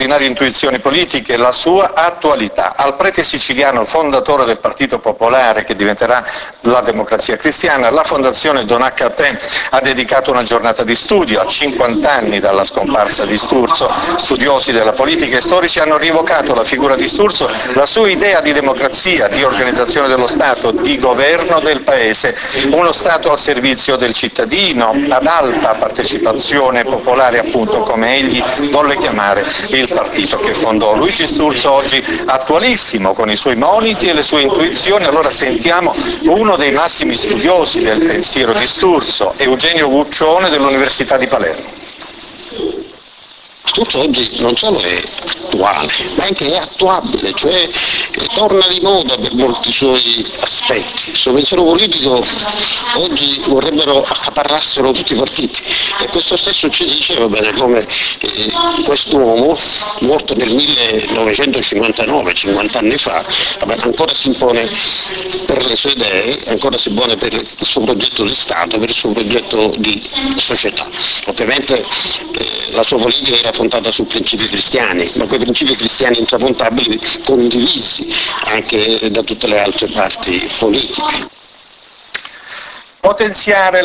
Intervista al TG3